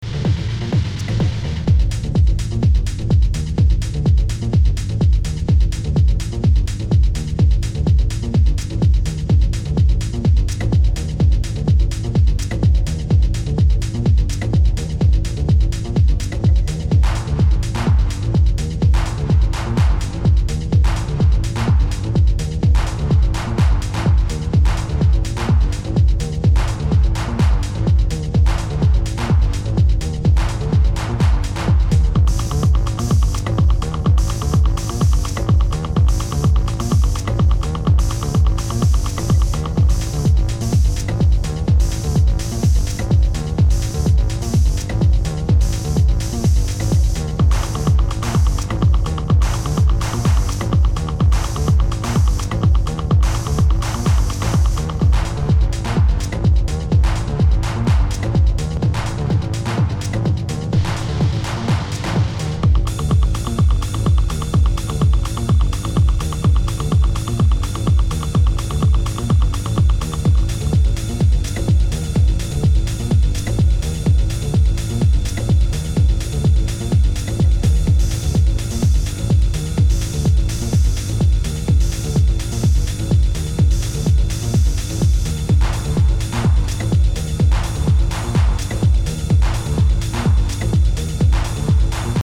4 straight banging techno work outs